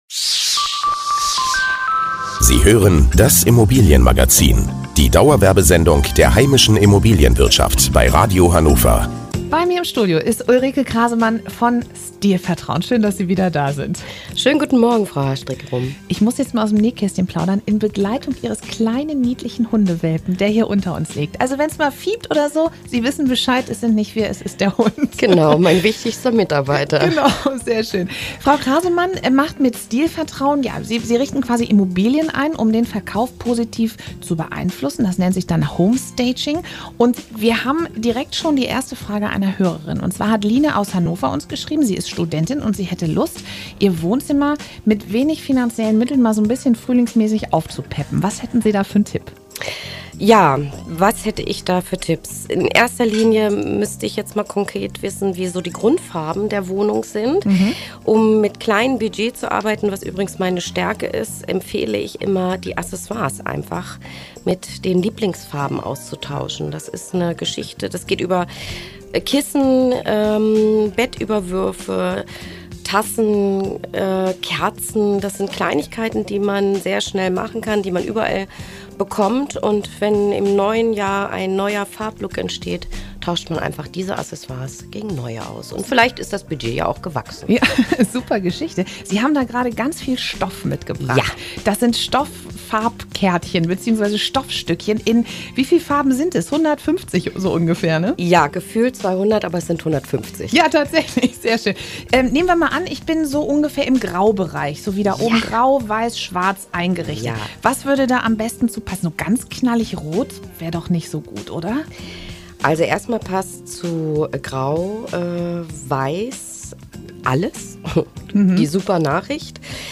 Radiobeitrag vom 29.03.2015